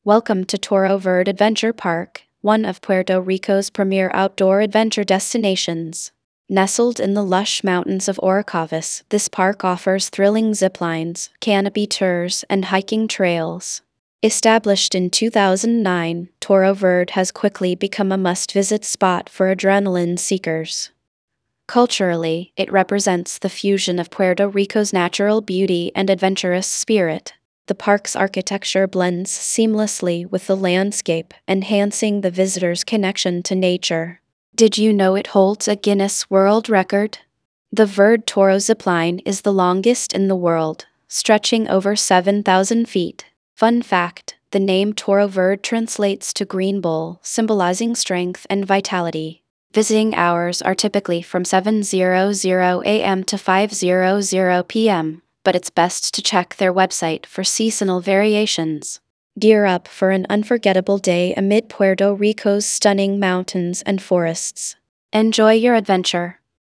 karibeo_api / tts / cache / f5fdb926f766b2bcbe31232e28eaf0ad.wav